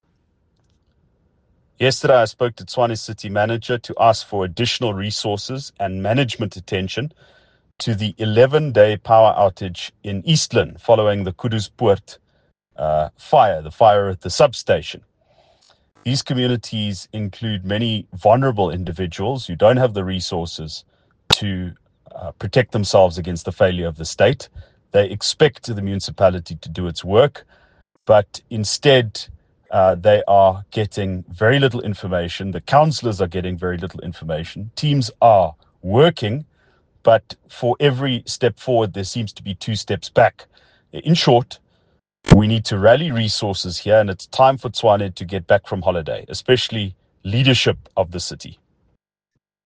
Afrikaans soundbites by Ald Cilliers Brink